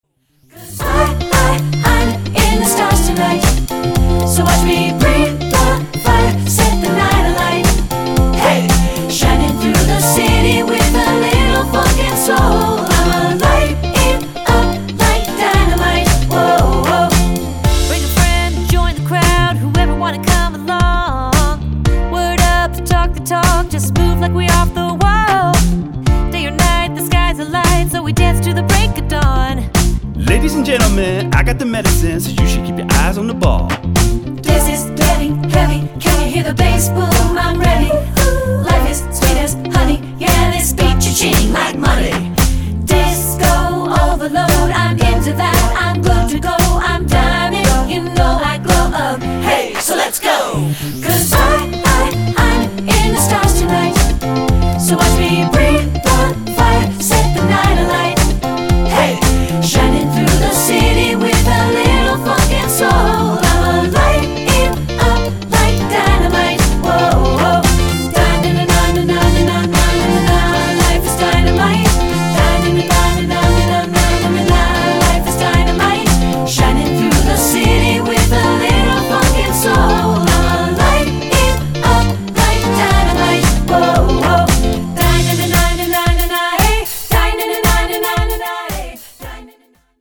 Choral Recent Pop Hits
3 Part Mix